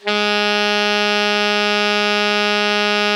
ALTO  MF G#2.wav